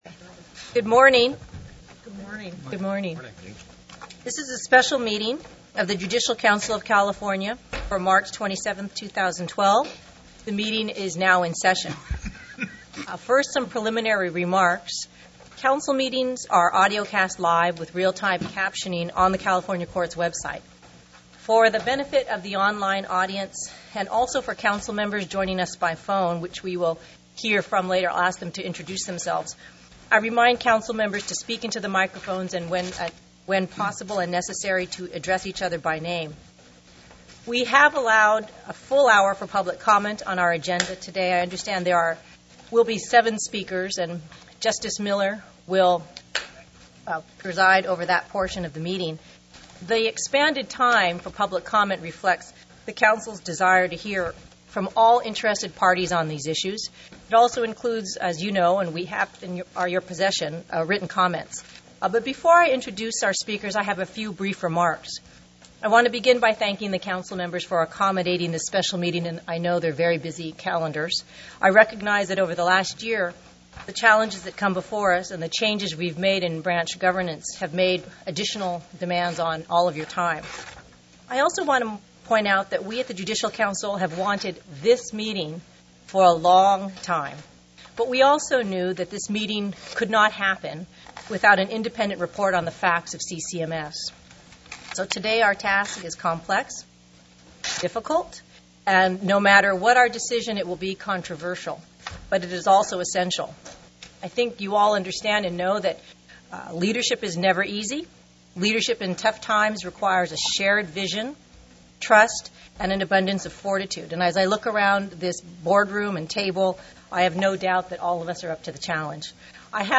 Public Comments (various speakers)